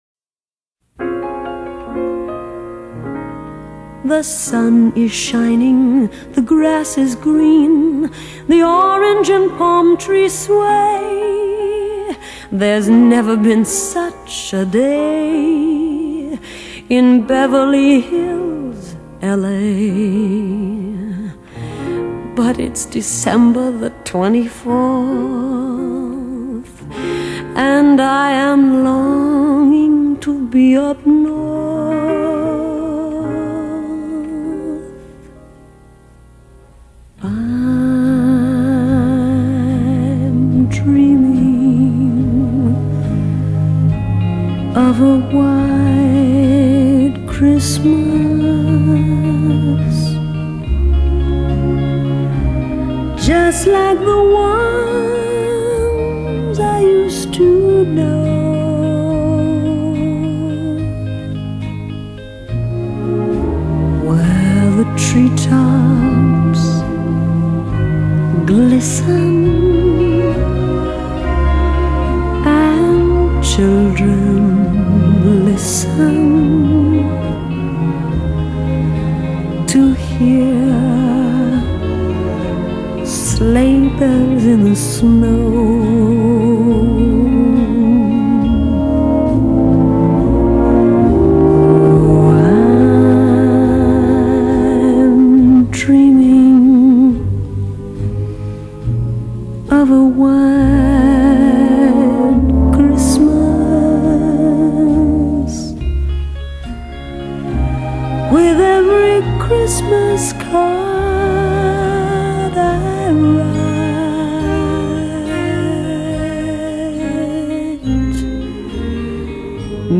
类　　别: Pop, Jazz, Christmas　　　　　　　　　　.